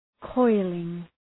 Προφορά
{‘kɔılıŋ}